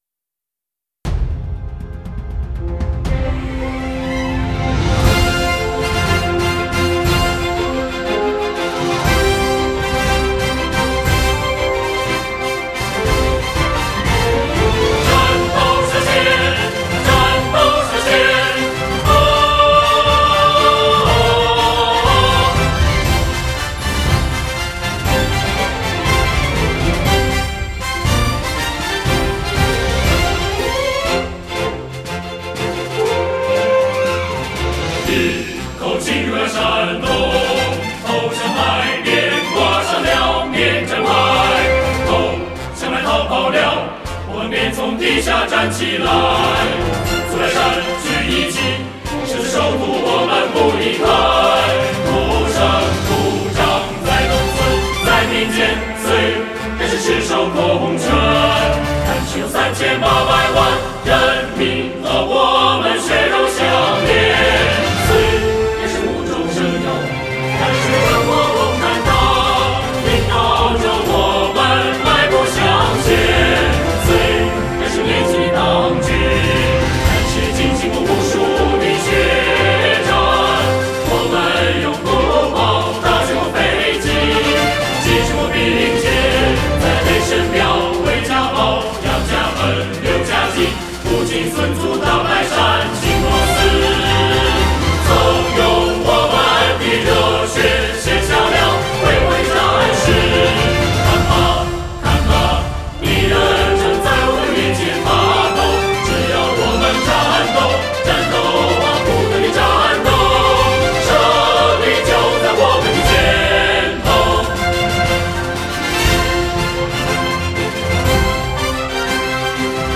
山东纵队进行曲（合唱）.mp3